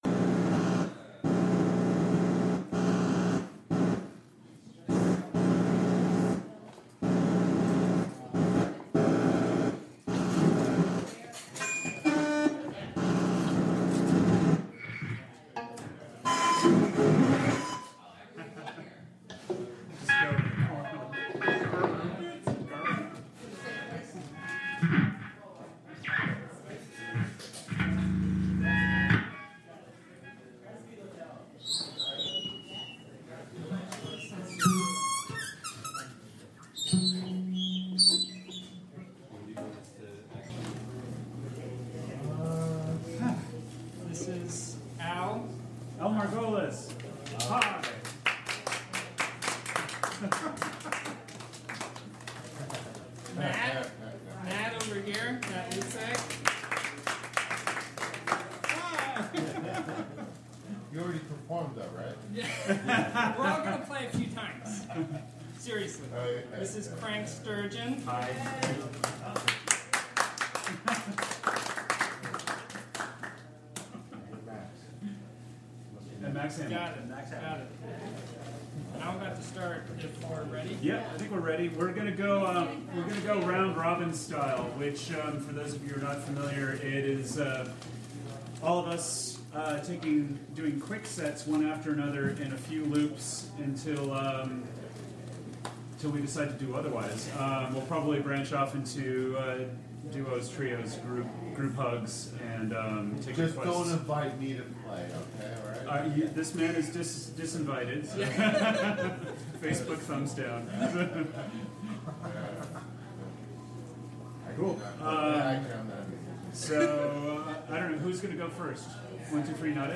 Recorded from a live webstream.